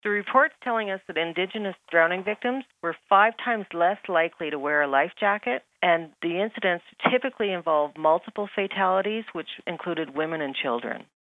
Type: PSA
320kbps Stereo